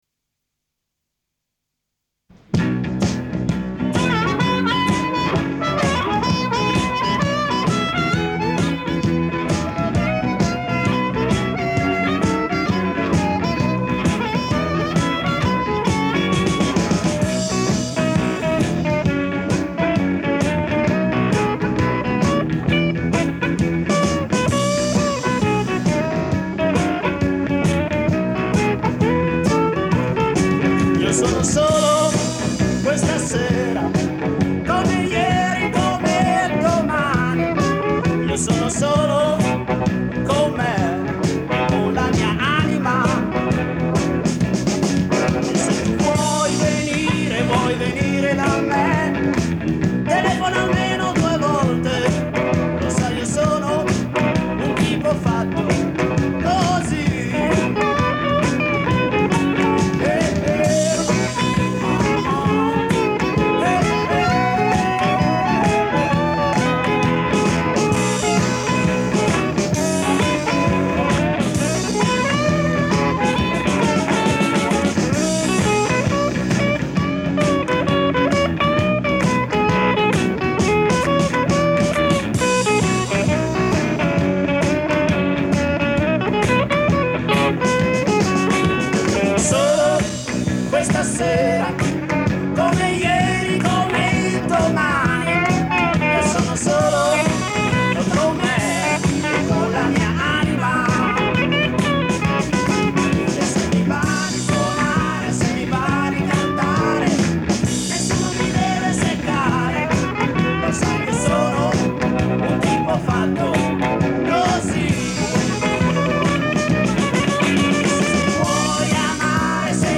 Collaboro, con grande soddisfazione, ad un suo progetto - UN SOGNO IN MEZZO AL MARE, realizzato a Venezia (ascolta
insieme a Fabio Treves e ad un gruppo di musicisti locali, "Il Matto e la Luna".